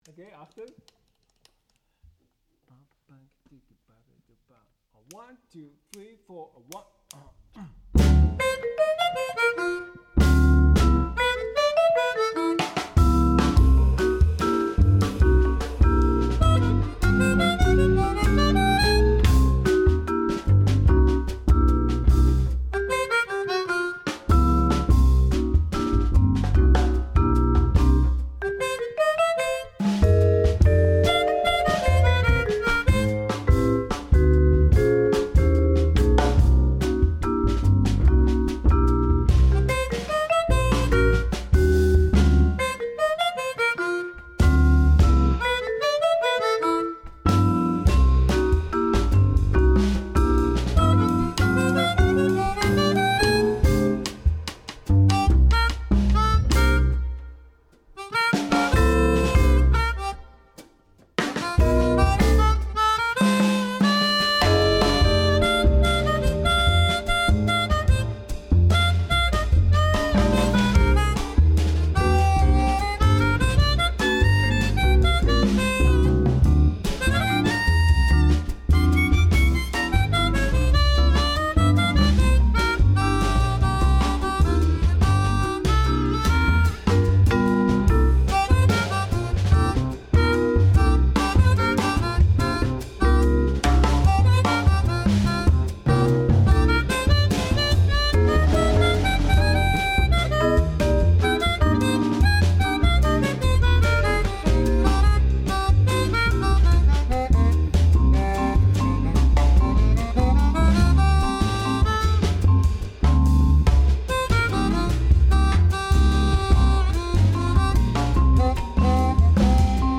odd meter Boogaloo in 7/4 Bluesform for Jazzcombo
listen to the piece played live at Radio Kulturhaus RP2